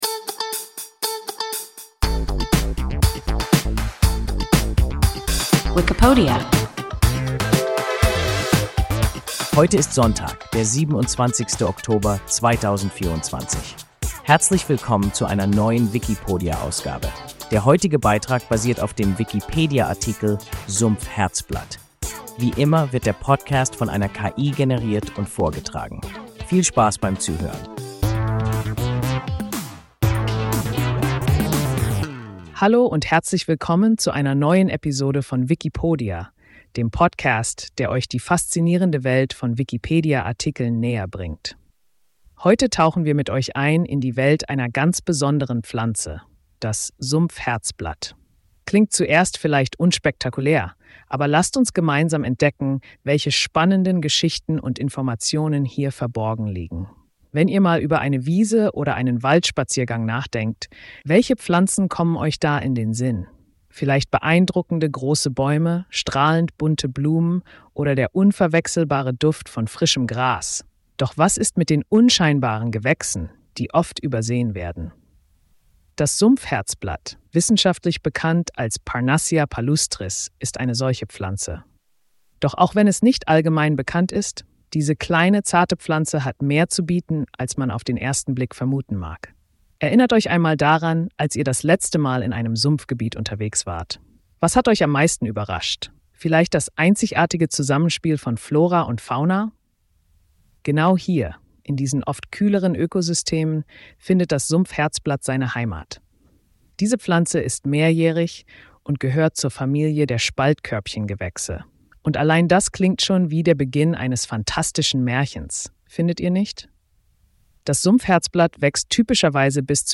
Sumpf-Herzblatt – WIKIPODIA – ein KI Podcast